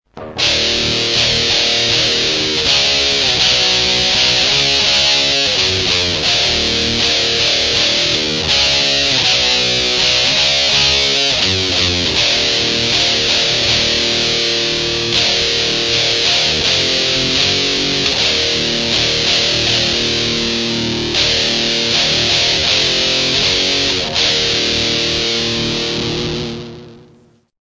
В этой статье мы собираем примочку и послушаем эти ужасные звуки )